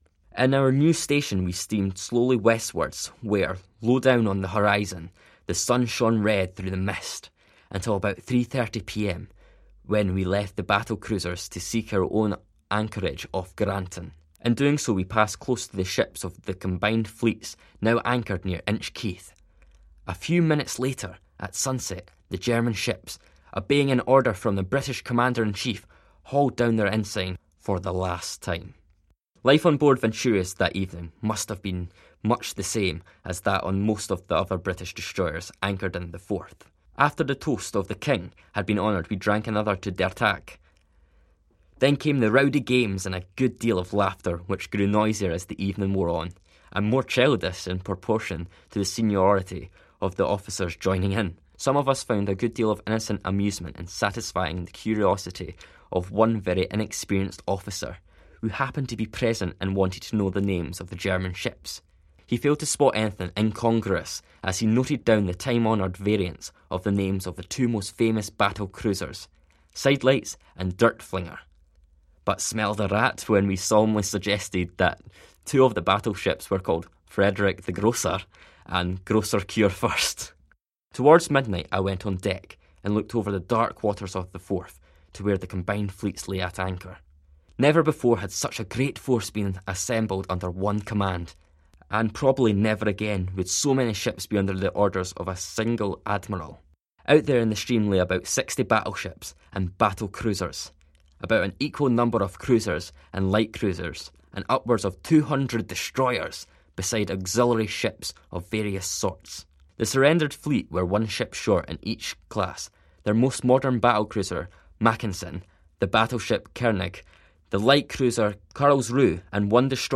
Here is a recording of the final chapter of his diary, covering the Surrender of the German High Seas Fleet in the Forth, November 2018.